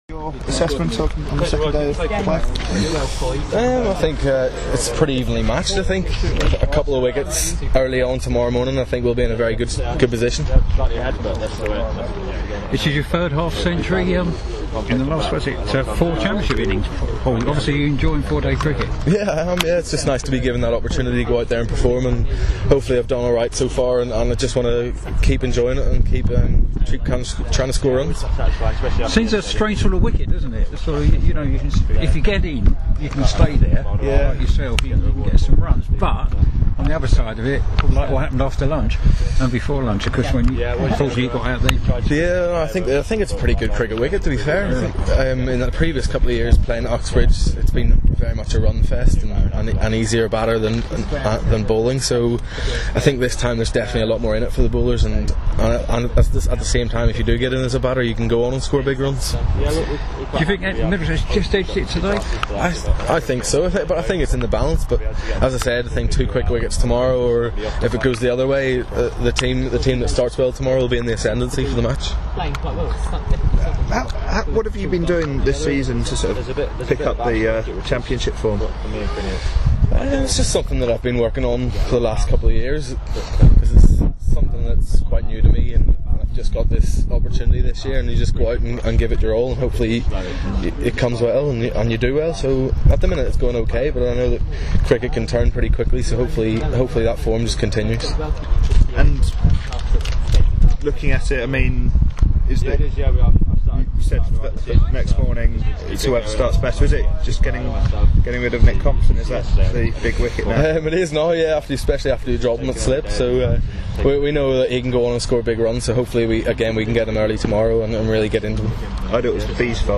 Paul Stirling of Middlesex, speaking after day 2 of Middlesex's County Championship match against Somerset at Uxbridge